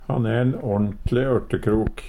ørtekrok - Numedalsmål (en-US)